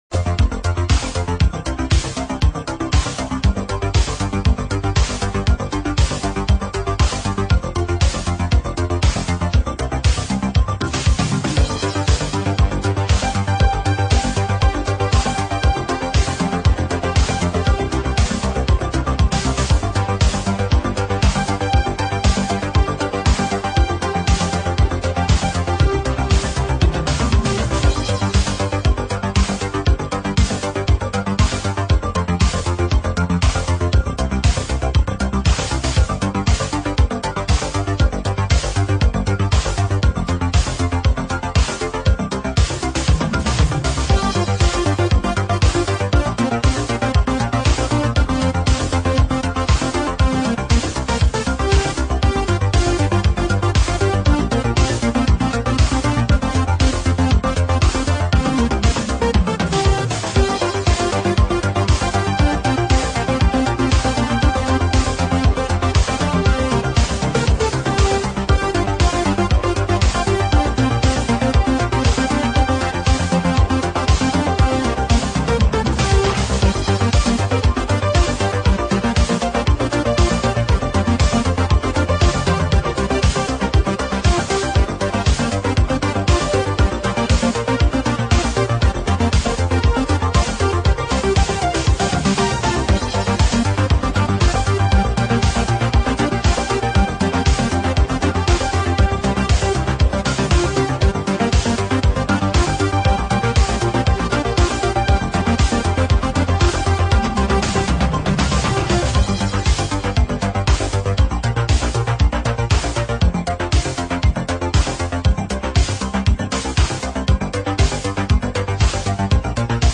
レトロ・フューチャーなサウンドが最高です！